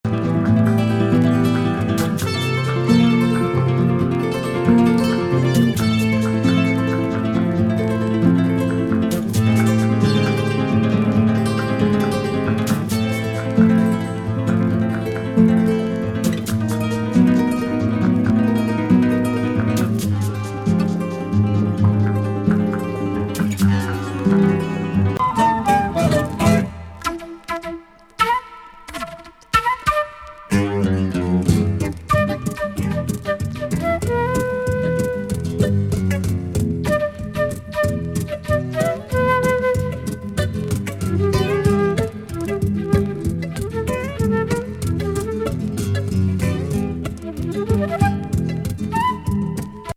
ドイツのギタリスト二人によるアコースティック・ギター・アンサンブル・
エレクトリック・シタールやアラビック・リュートも良い塩梅。